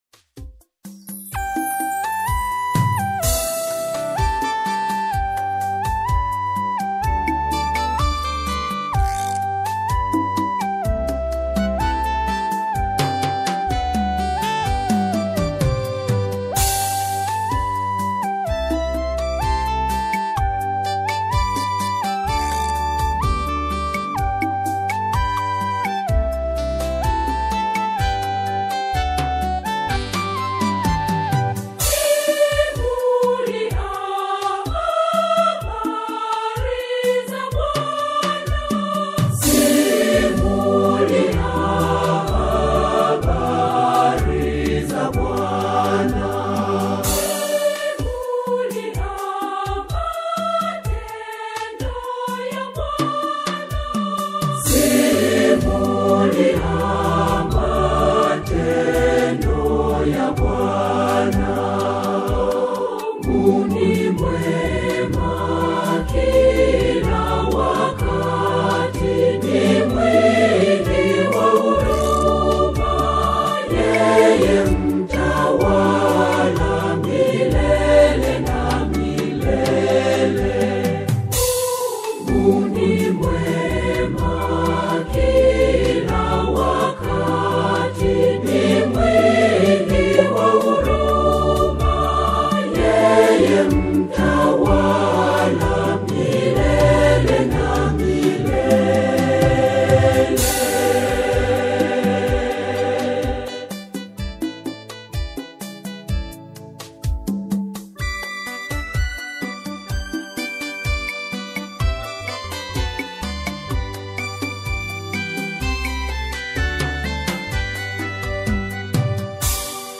The harmonically rich and rhythmically driving single